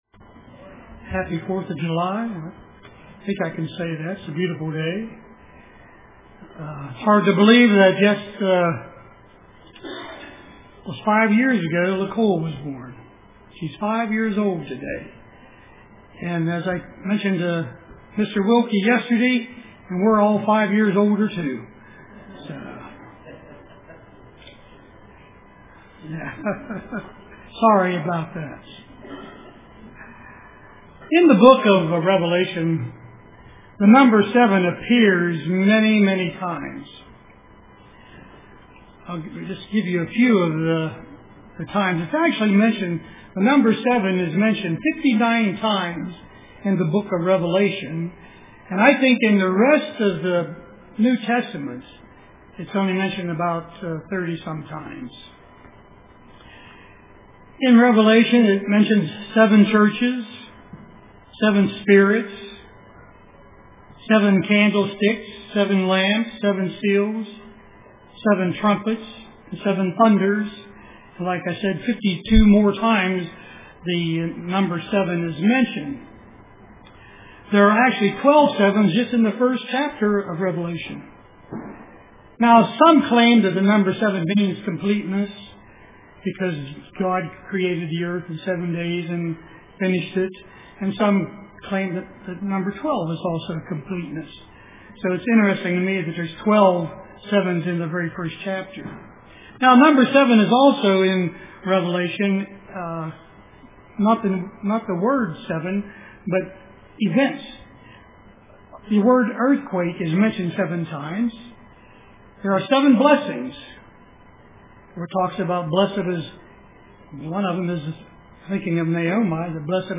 Print The Seven Spirits of God UCG Sermon Studying the bible?